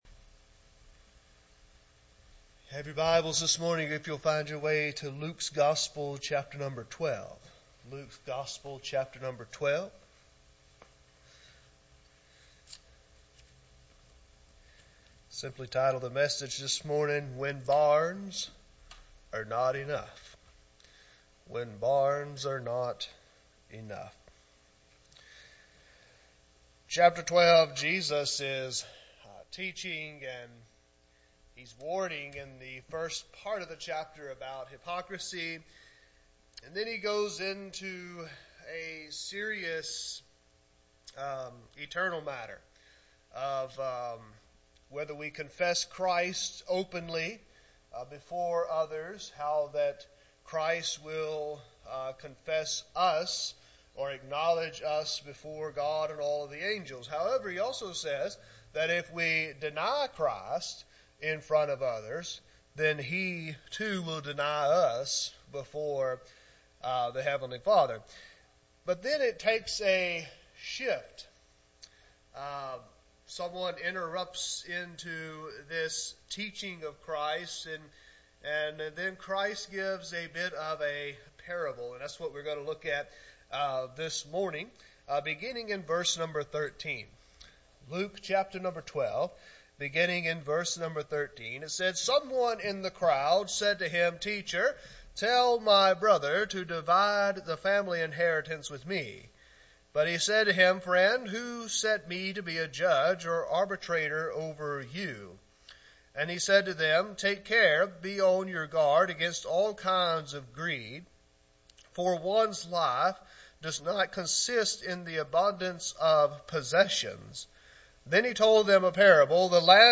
Sermons | West Acres Baptist Church